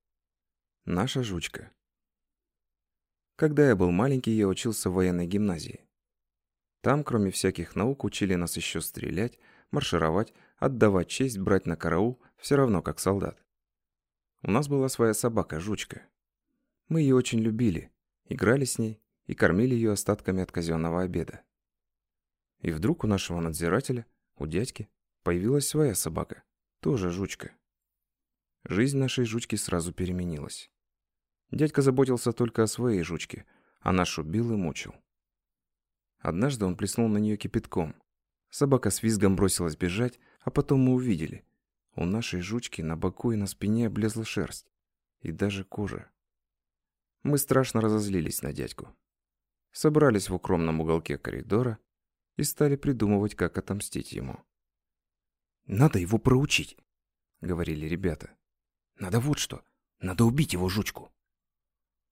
Аудиокнига Наша Жучка | Библиотека аудиокниг